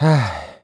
Riheet-Vox_Sigh_kr.wav